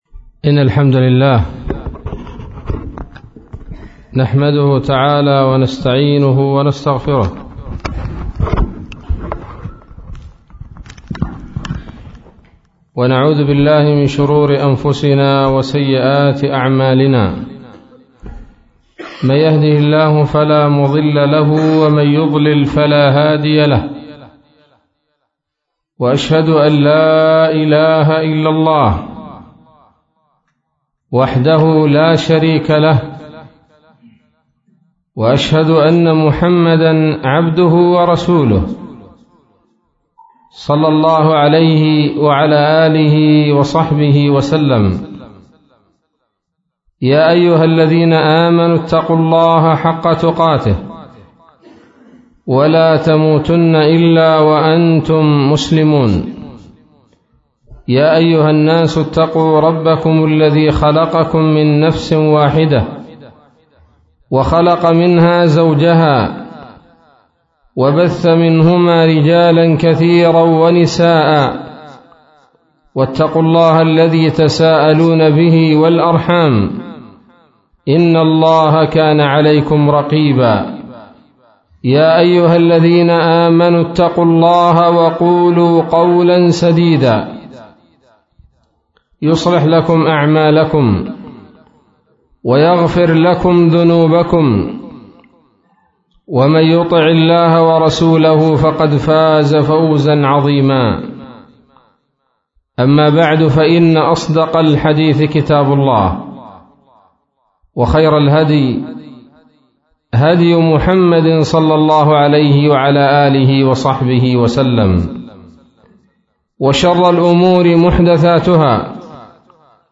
محاضرة قيمة بعنوان: ((من مواقف يوم القيامة
منطقة التربة